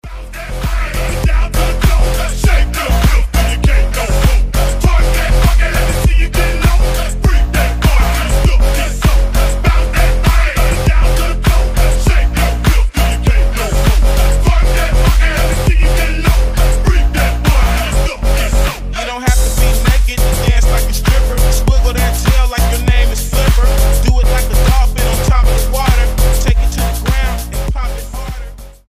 • Качество: 320, Stereo
мощные басы
качающие
взрывные
G-House
Mashup
Взрывной G-house